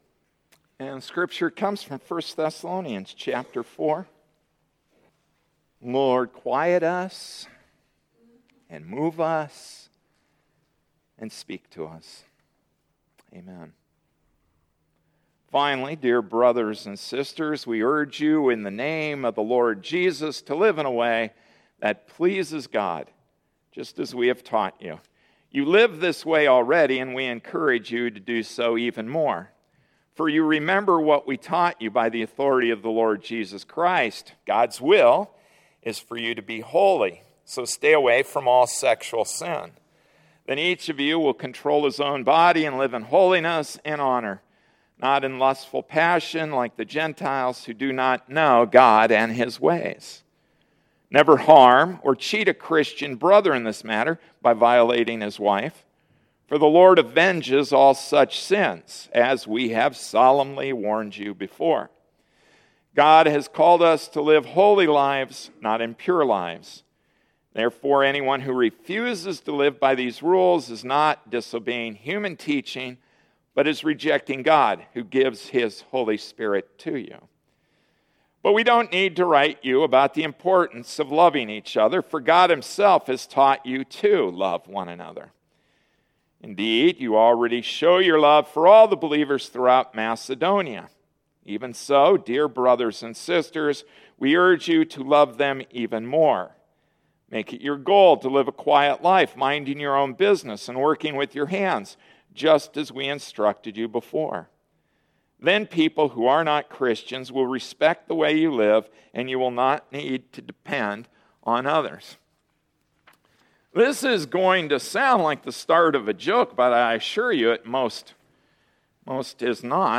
August 18, 2013 Live Holy Lives Passage: 1 Thessalonians 4:1-12 Service Type: Sunday Morning Service “Live Holy Lives” 1 Thessalonians 4:1-12, 5:12-22 Introduction: When a liberal and a conservative agree, it’s time to act.